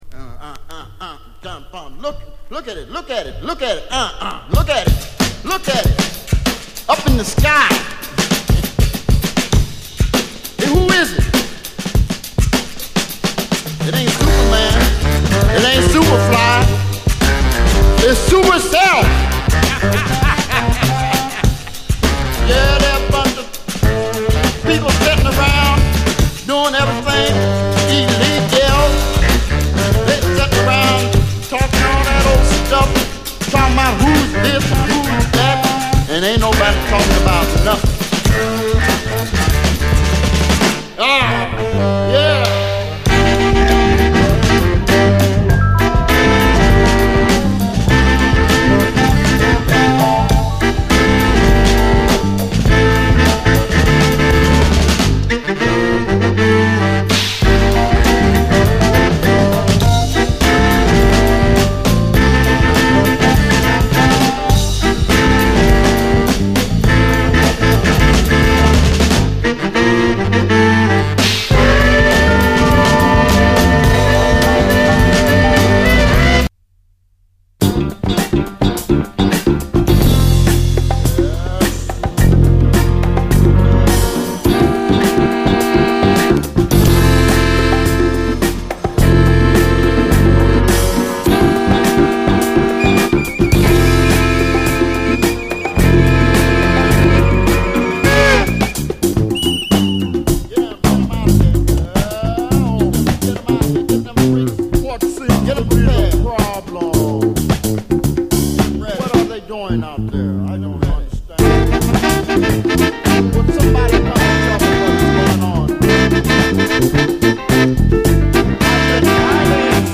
JAZZ FUNK / SOUL JAZZ, JAZZ
ドラム・ブレイク付きのジャズ・ファンク
ビート感といい、とにかくファット！